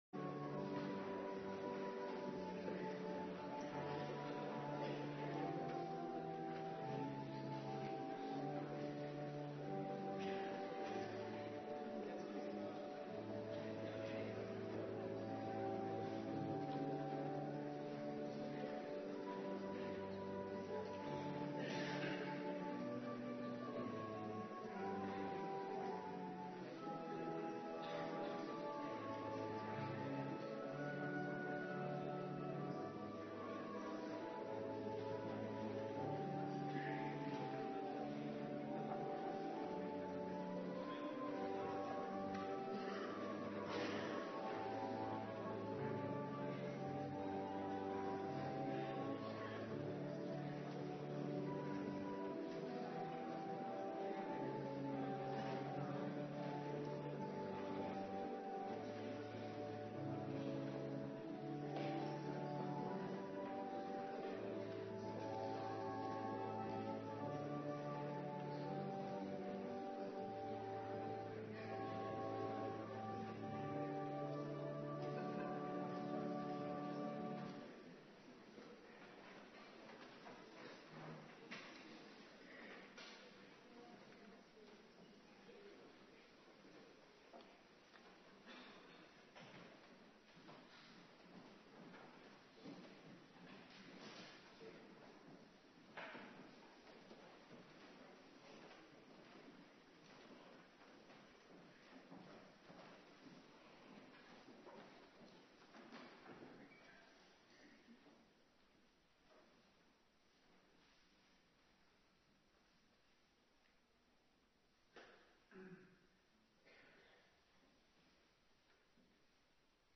Avonddienst dankdag
19:30 t/m 21:00 Locatie: Hervormde Gemeente Waarder Agenda